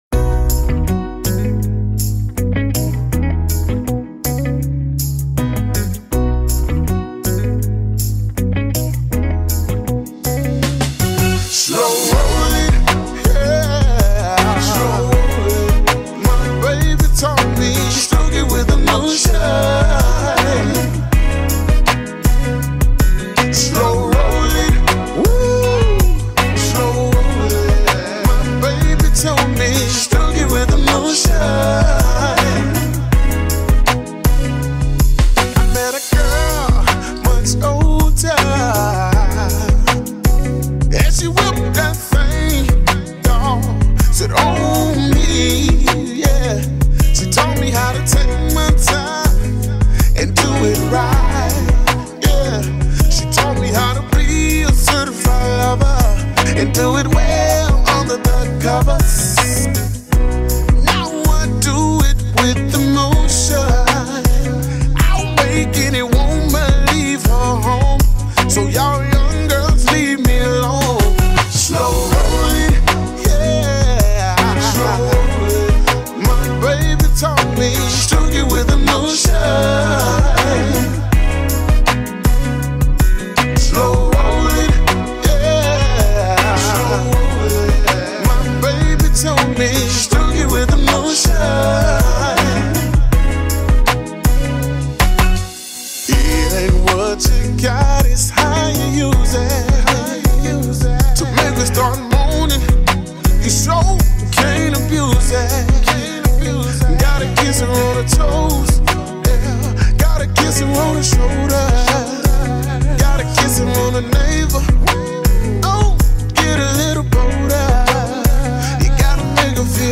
Genre: Southern Soul.